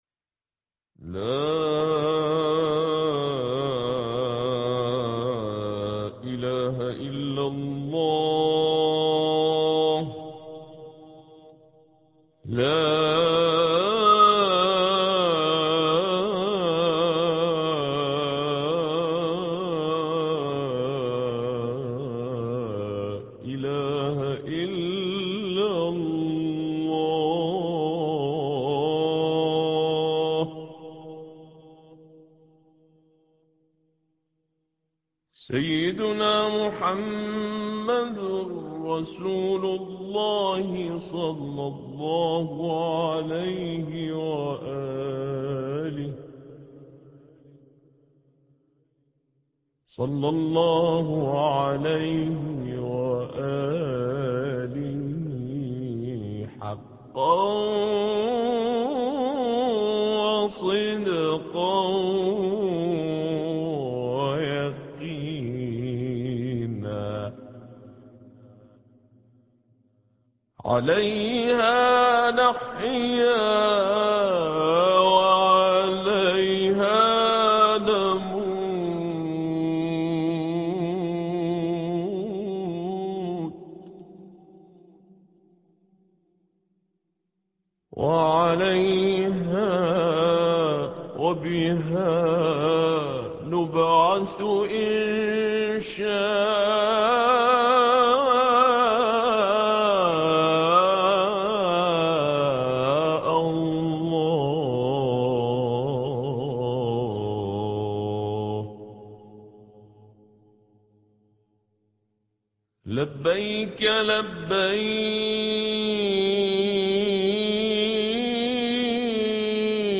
ابتهالات